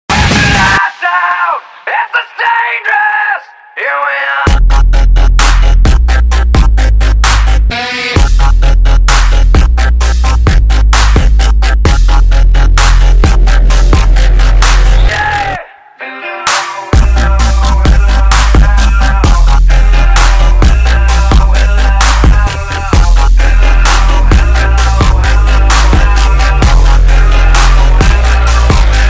Liberty of the Seas tying sound effects free download
Liberty of the Seas tying up in Coco Cay 😍 Those big heavy lines, not so easy to pull in!